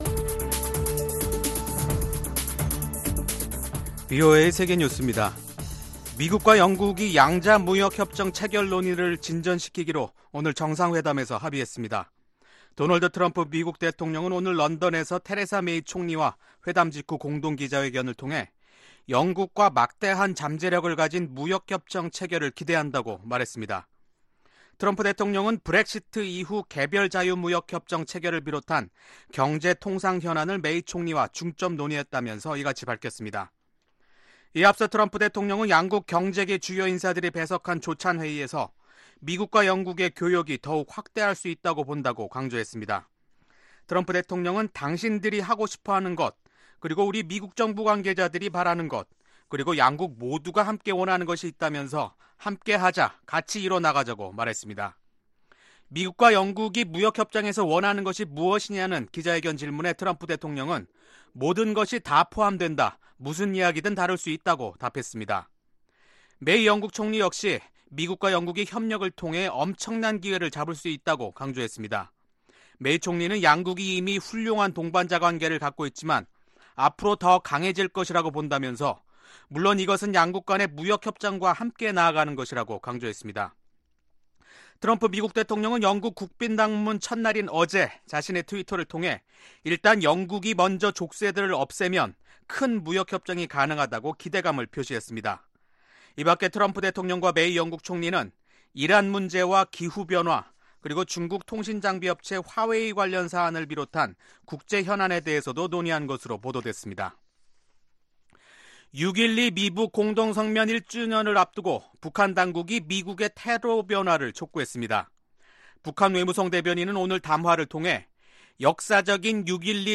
VOA 한국어 간판 뉴스 프로그램 '뉴스 투데이', 2019년 6월 4일 3부 방송입니다. 마이크 폼페오 미국 국무장관은 북한의 최근 단거리 미사일 시험발사에 대해 안보리 결의 위반 가능성을 제기했습니다. 미국은 북한의 불법적인 선박간 환적에 대한 단속을더욱 강화할 것이라고 국무부 군축·국제안보담당 차관이 밝혔습니다.